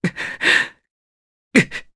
Kasel-Vox_Sad_jp.wav